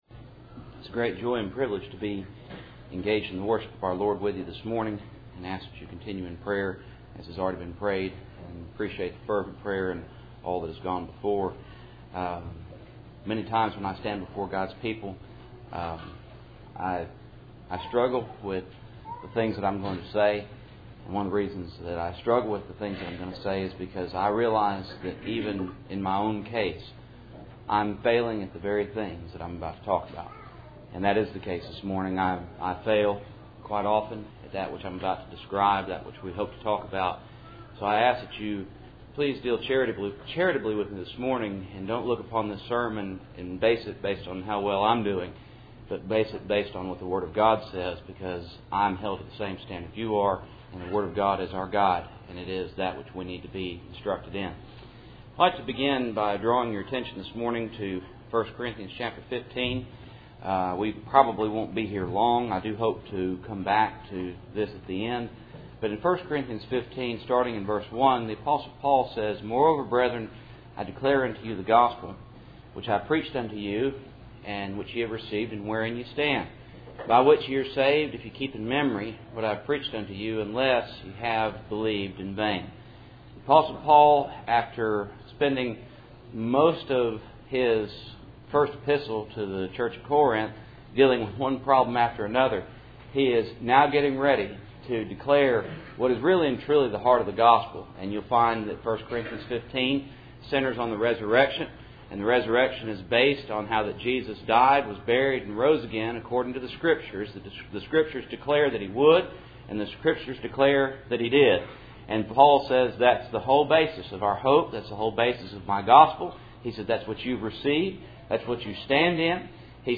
Passage: 1 Corinthians 15:1-2 Service Type: Cool Springs PBC Sunday Morning